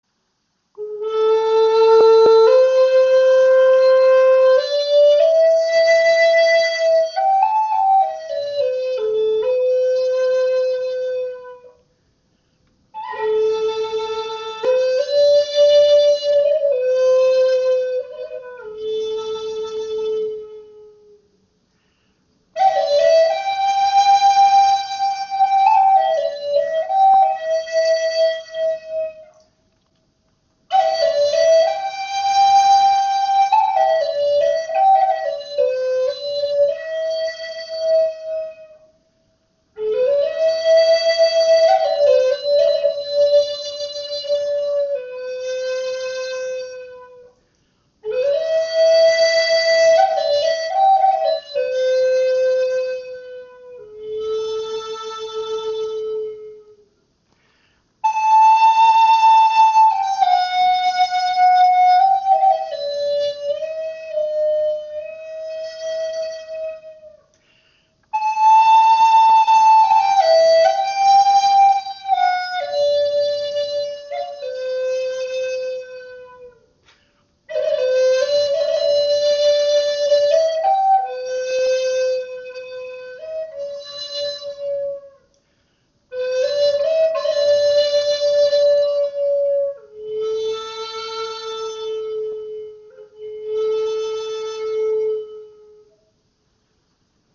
Klangbeispiel
Flötenkörper aus Nussbaumholz 45 cm lang, 5 Grifflöcher